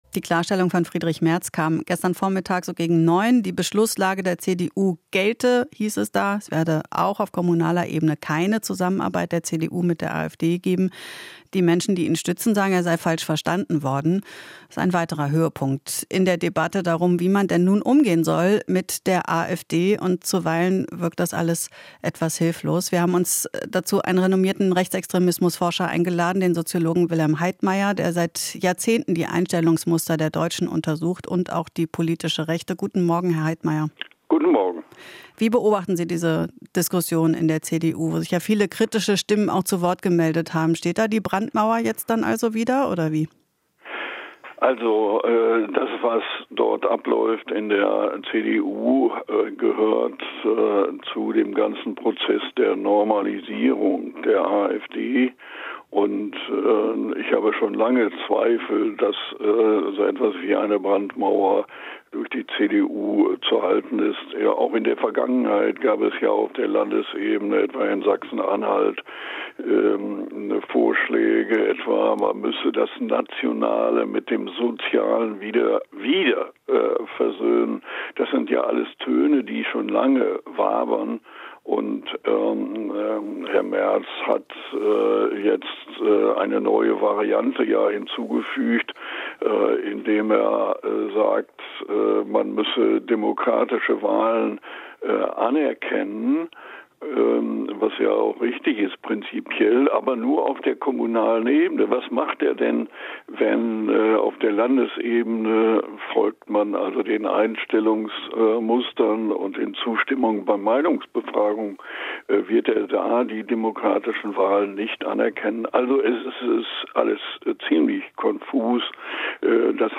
Interview - Rechtsextremismus-Experte: CDU-Debatte ist Teil der AfD-Normalisierung